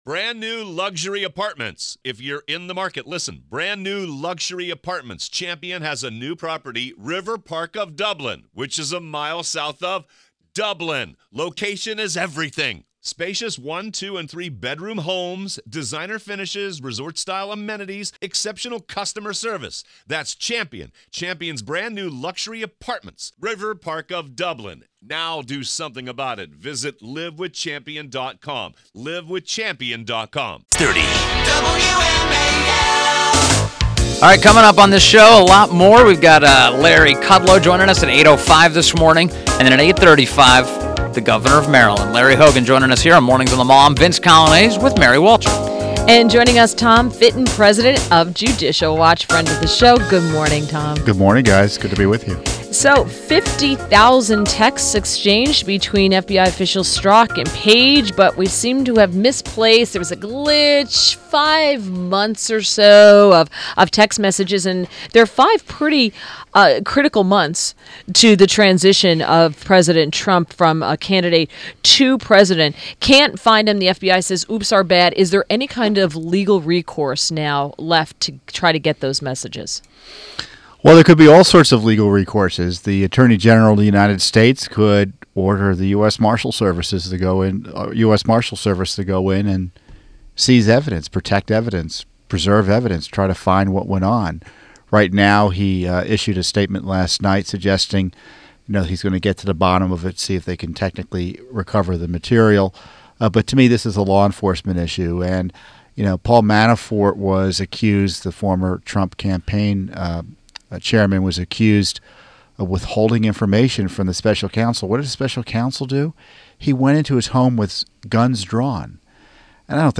WMAL Interview - TOM FITTON - 01.23.18
INTERVIEW — TOM FITTON – President of Judicial Watch – discussed the FBI texts and the memo.